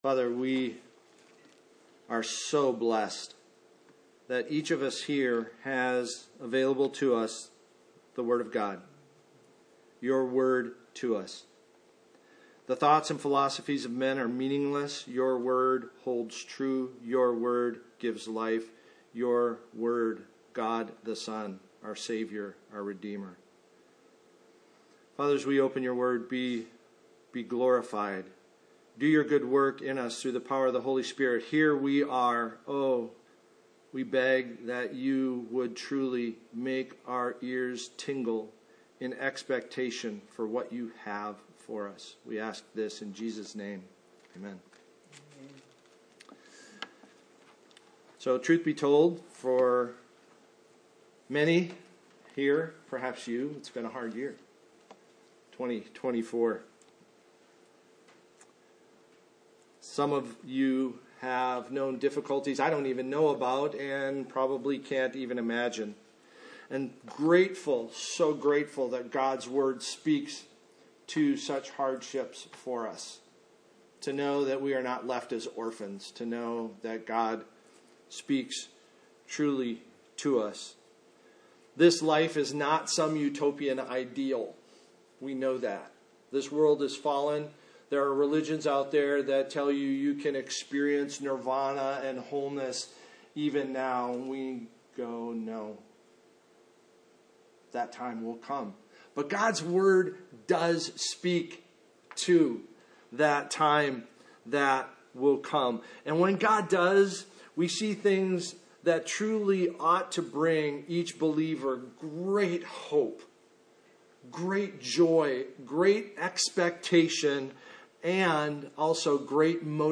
Passage: Various Service Type: Sunday Morning Paul exhorts the saints to set their minds on things above, next things.